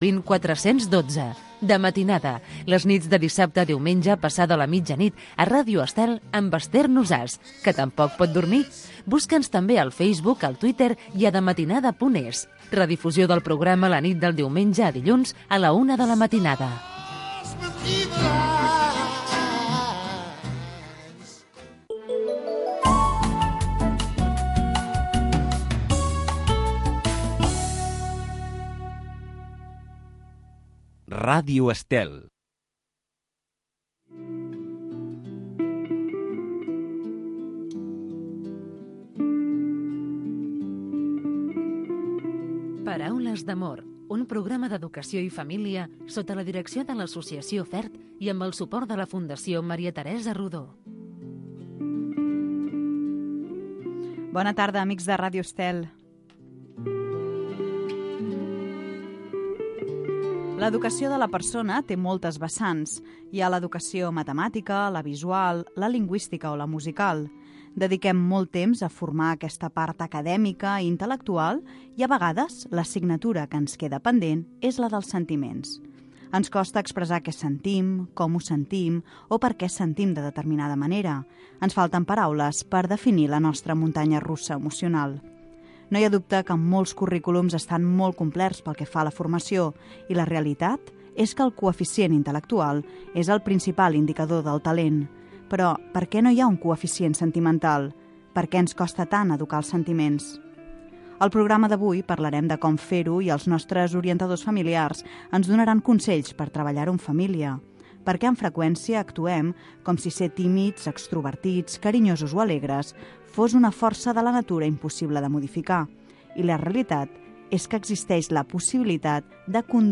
Paraules d'amor. Un programa amb entrevistes i tertúlia sobre la família amb clau de valors humans, produït pel l'associació FERT.